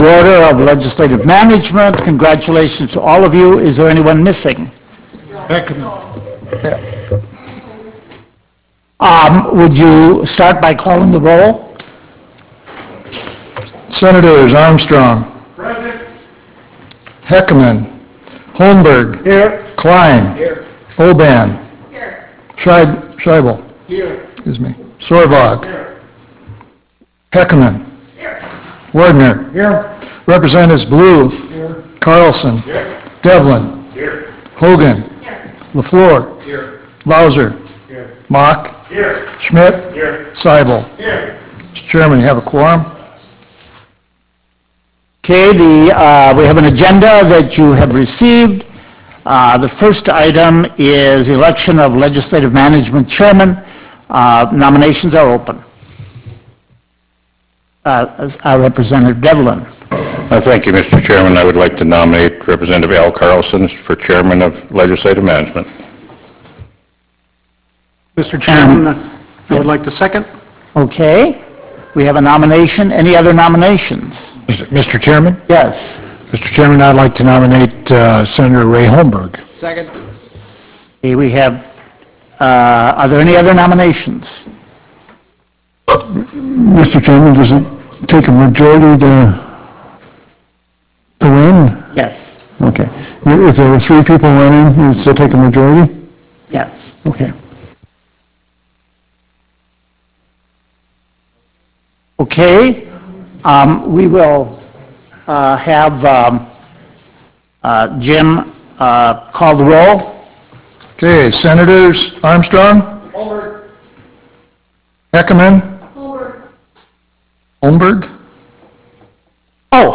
Prairie Room State Capitol Bismarck, ND United States